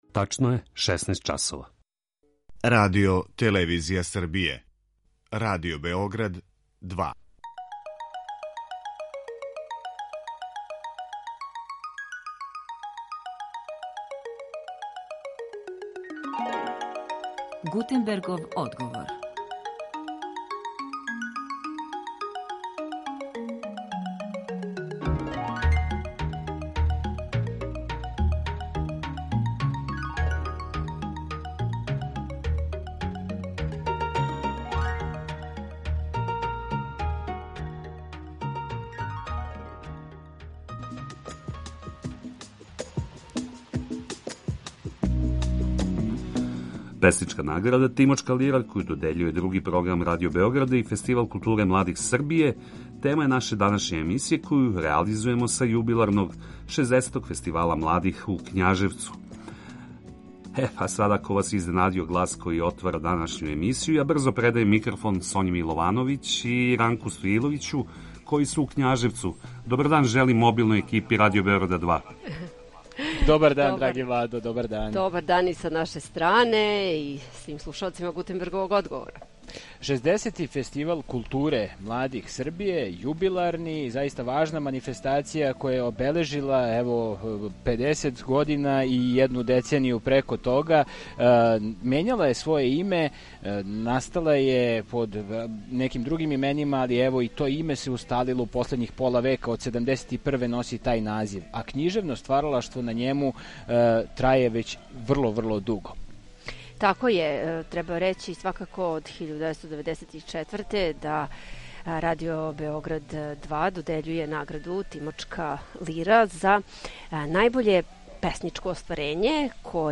Песничка награда „Тимочка лира”, коју додељују Други програм Радио Београда и Фестивал културе младих Србије, тема је наше данашње емисије, коју реализујемо са јубиларног, 60. Фестивала младих у Књажевцу.